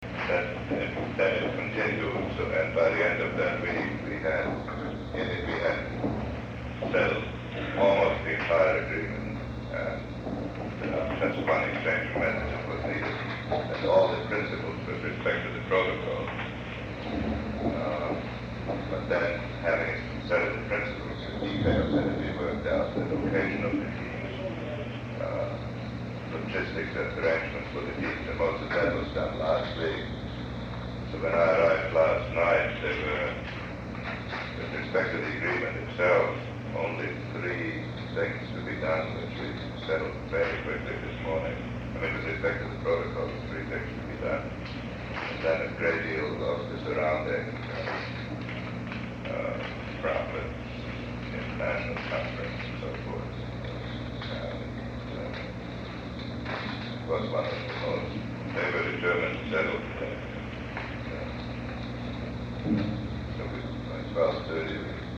Sample conversation: January 23, 1973, White House Cabinet Room, 8:38 - 9:05 pm
7. Kissinger discusses the negotiating conclusion (